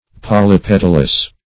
Polypetalous \Pol`y*pet"al*ous\, a. [Poly- + petal.] (Bot.)